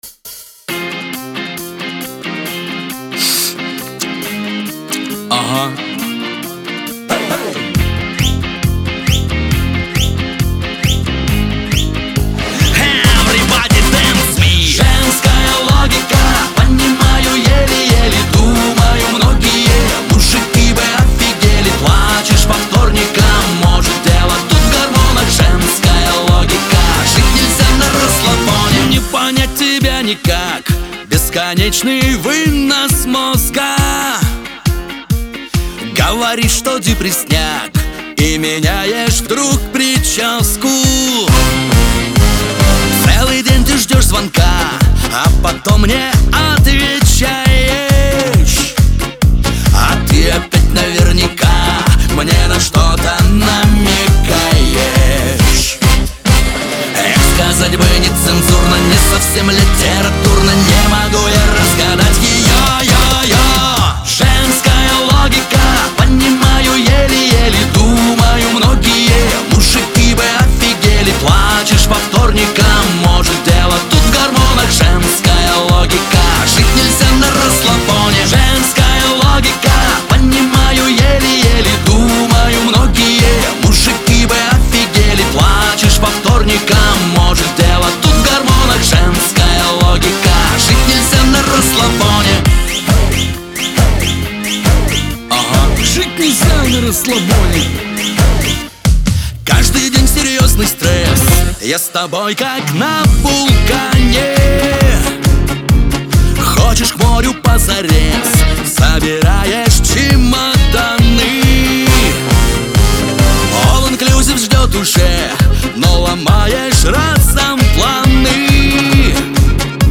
весёлая музыка
Лирика